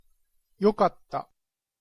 Prononciation-de-yokatta.mp3